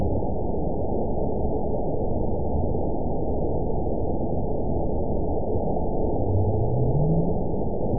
event 920529 date 03/28/24 time 21:05:00 GMT (1 year, 1 month ago) score 9.55 location TSS-AB04 detected by nrw target species NRW annotations +NRW Spectrogram: Frequency (kHz) vs. Time (s) audio not available .wav